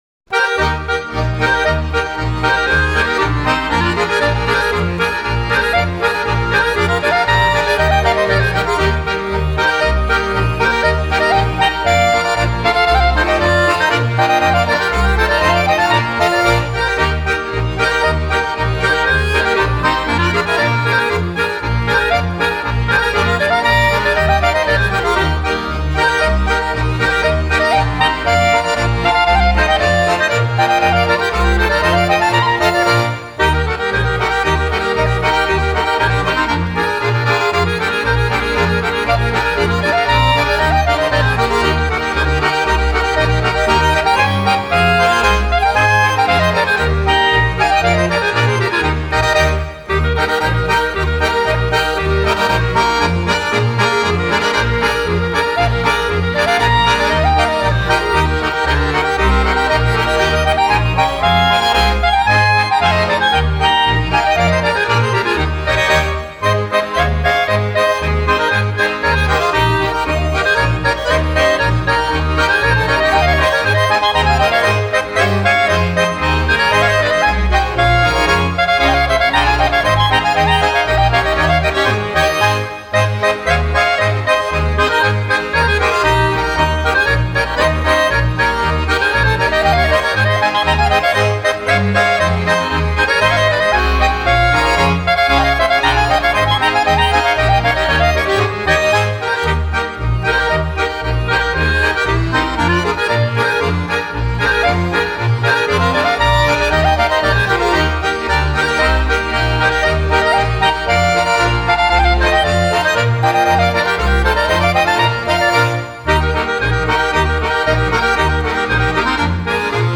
(Schottisch)